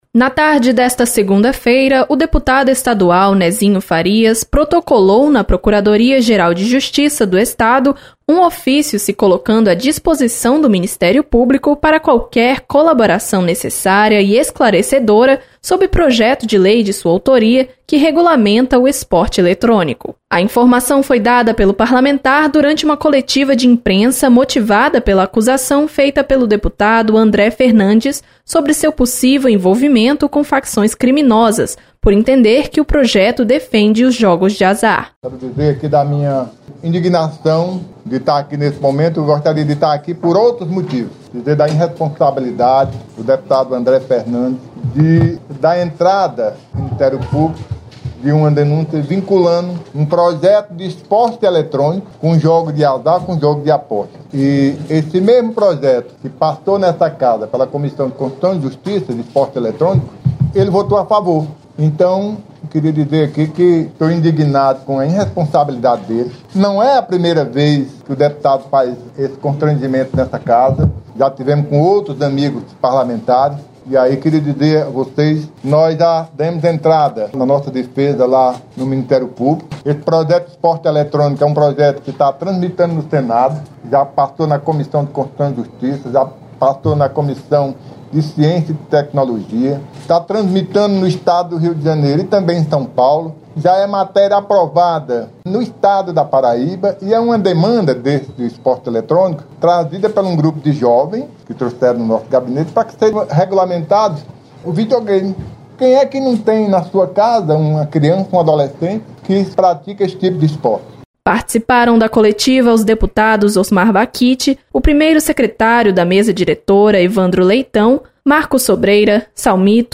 Coletiva